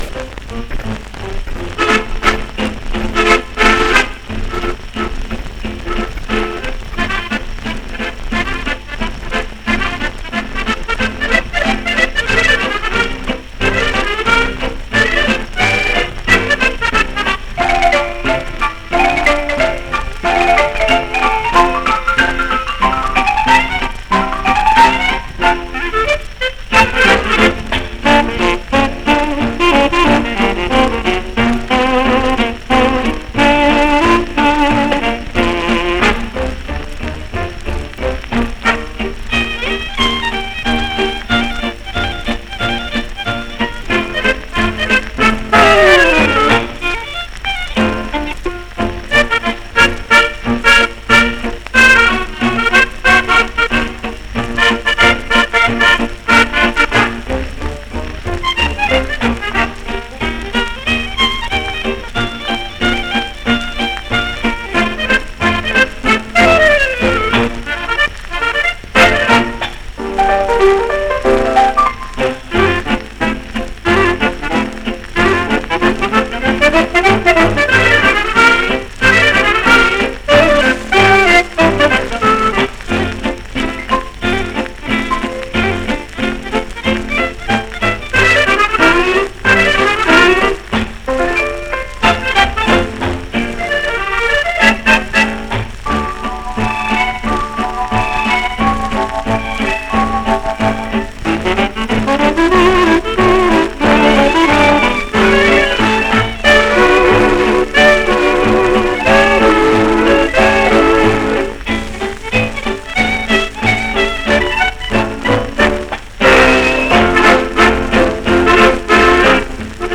Audio Here is a selection of Russian music typically found on X-Ray recordings. The quality is often poor as the recordings deteriorated quickly.